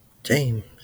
IPA/dʒeɪmz/ lub /ˈdʒeɪms/